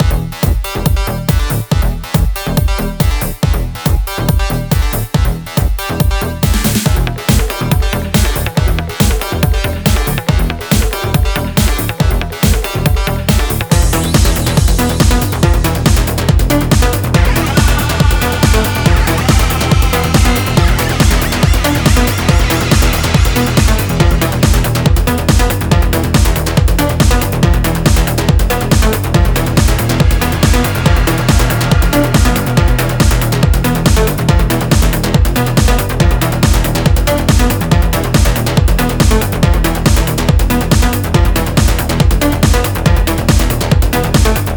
Накидал тут пример, моно-ритм секция, и всякие делейные эффекты по серёдке и верхушке.